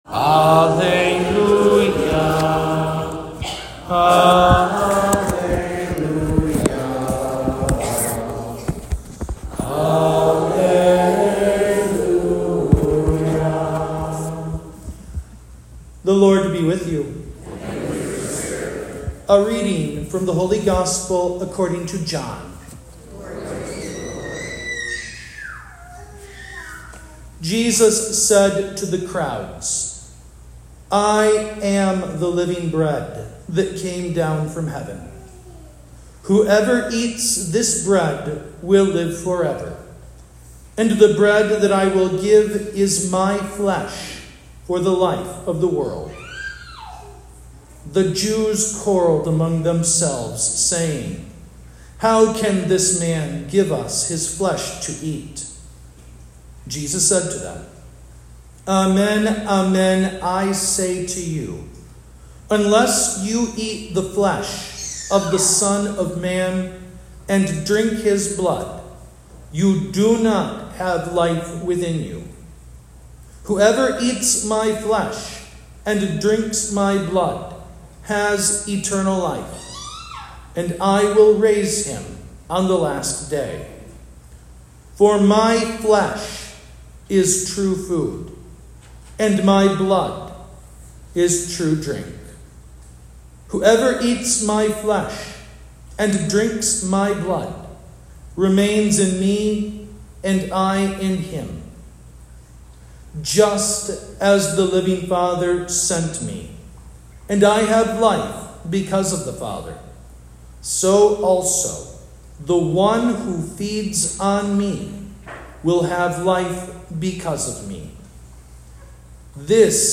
Homily-20th Sunday of Ordinary Time (B)